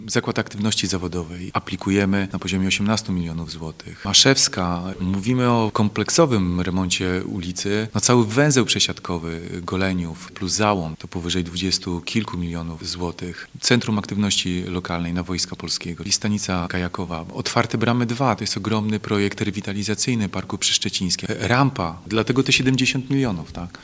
Planowane na przyszły rok inwestycje realizowane będą różnych punktach miasta, ale dotyczyć będą także różnych dziedzin życia. – Goleniów zamieni się w plac budowy, ale to projekty ważne, otwierające mieszkańcom wiele nowych możliwości – dodaje burmistrz.